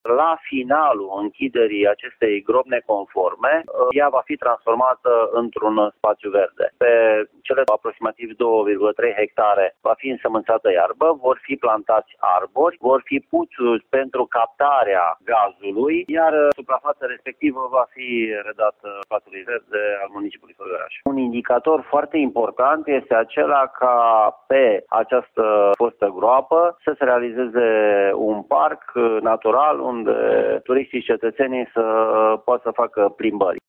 Lucrările de închidere încep astăzi, iar pe locul ocupat în prezent de groapa de gunoi se va amenaja un spațiu verde, spune primarul municipiului Făgăraș, Gheorghe Sucaciu: